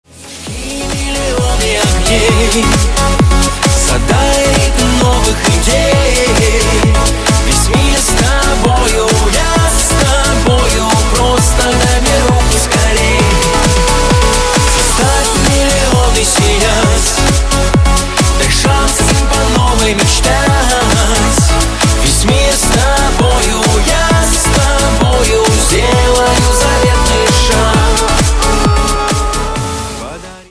• Качество: 128, Stereo
поп
мотивирующие
dance
club
подвижные
танцевальные
клубные